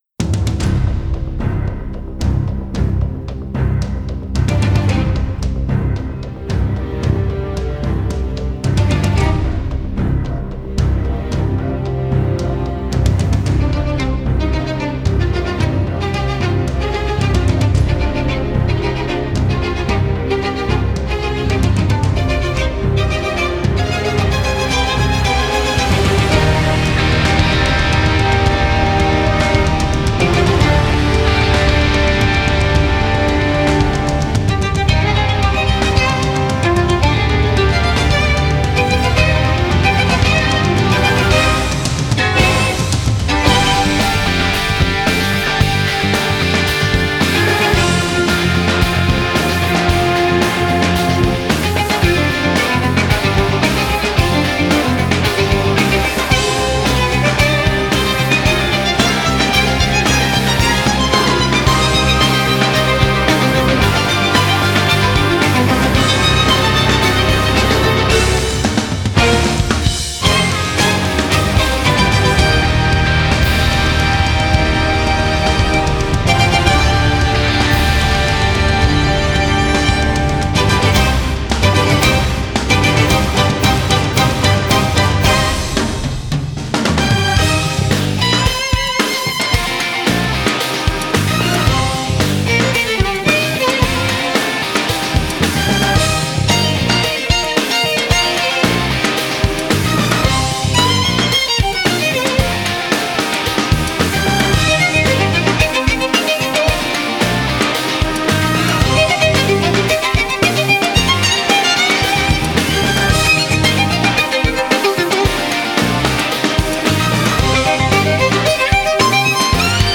Жанры: Классика, Кроссовер